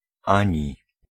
Ääntäminen
Ääntäminen Tuntematon aksentti: IPA: /ˈaɲi/ Haettu sana löytyi näillä lähdekielillä: puola Käännös Ääninäyte Konjunktiot 1. neither US US 2. nor US 3. or US UK Esimerkit Nie chce mi się ani jeść, ani pić.